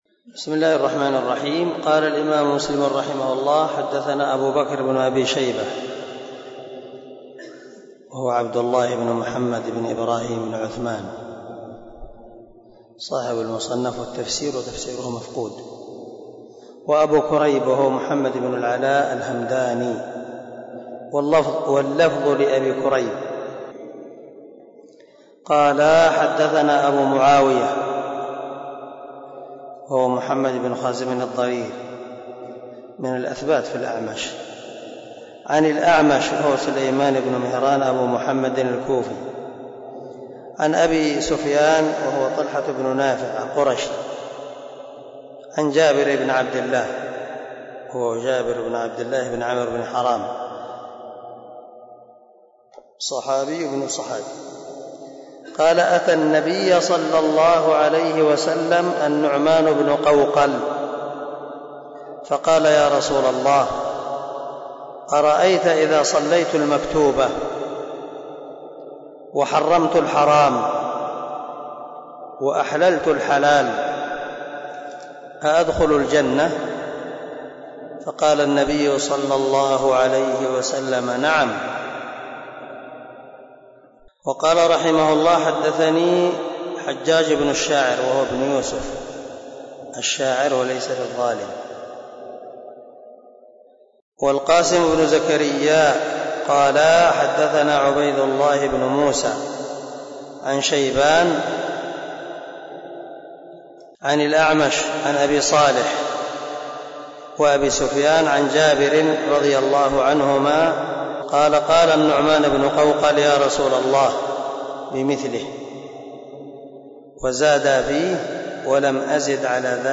008الدرس 7 من شرح كتاب الإيمان حديث رقم ( 15 - 16 ) من صحيح مسلم